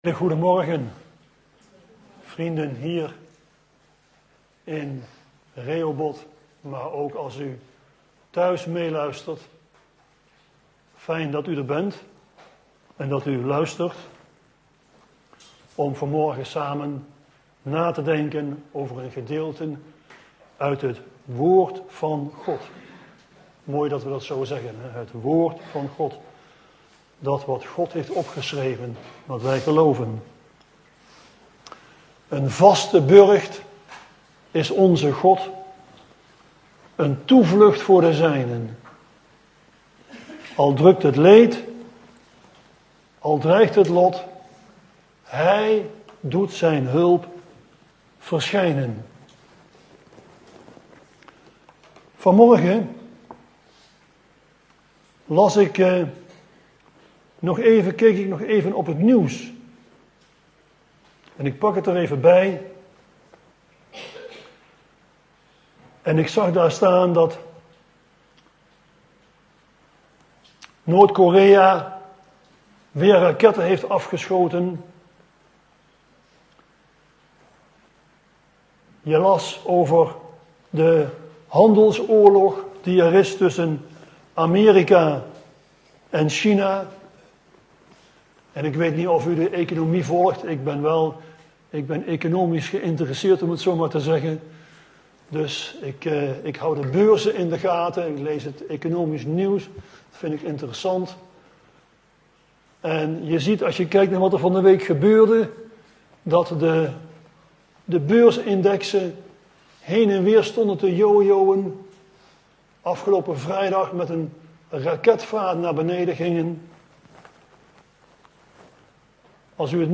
In de preek aangehaalde bijbelteksten (Statenvertaling)Psalmen 461 Een lied op Alamoth, voor den opperzangmeester, onder de kinderen van Korach.